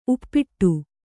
♪ uppāṭa